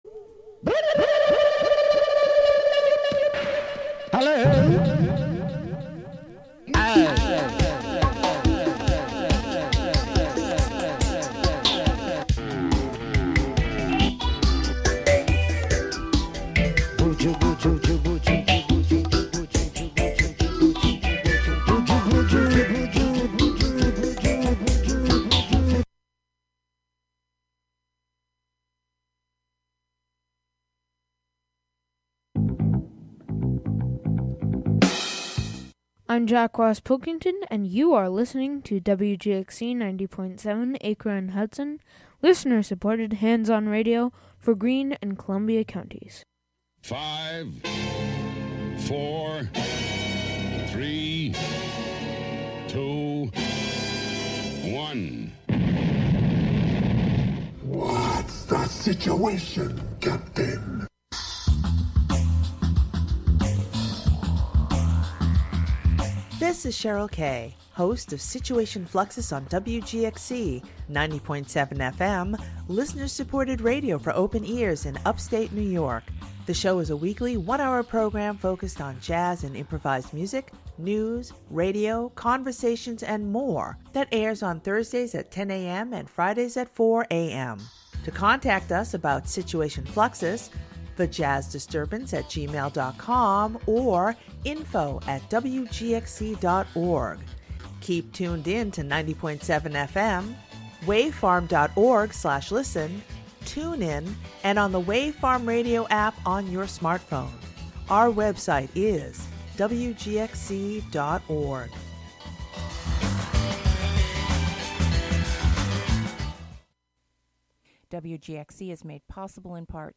A radio series of proprioceptive exercises, interviews about practices of communication, and archival sound.
Presented monthly as a combination of live and prerecorded sessions.